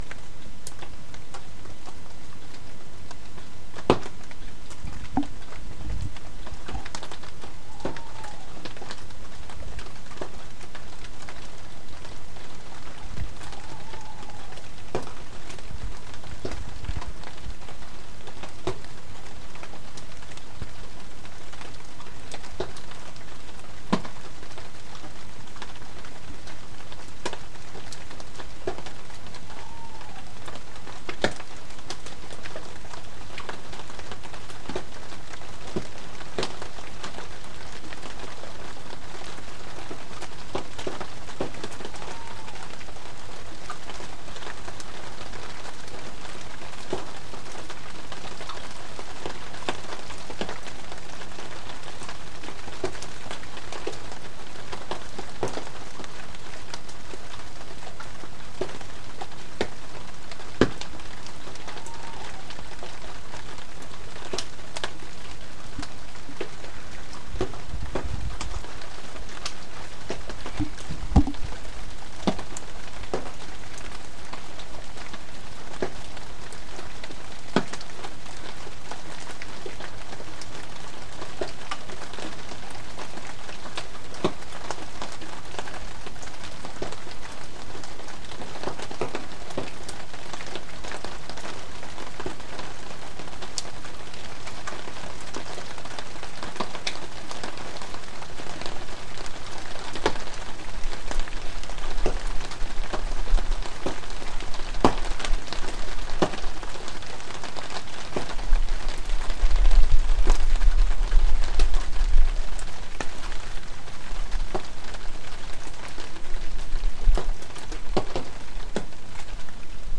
Owl in the rain